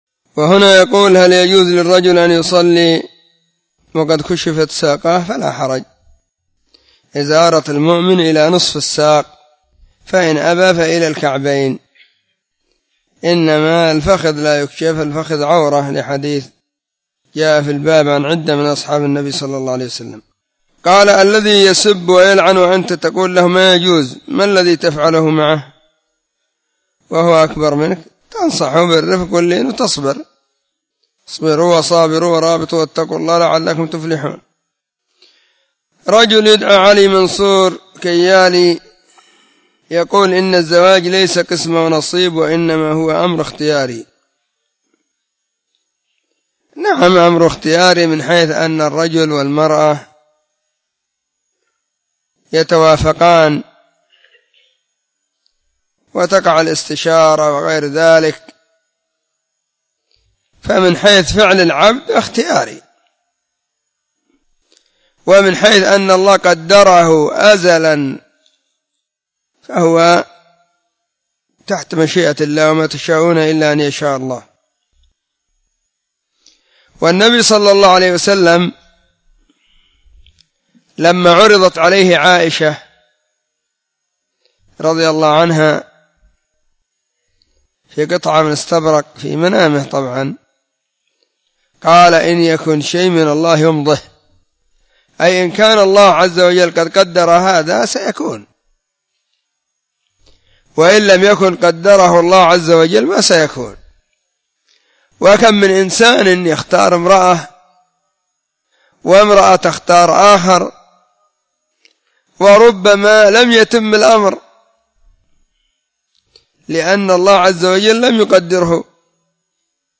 📢مسجد – الصحابة – بالغيضة – المهرة، اليمن حرسها الله.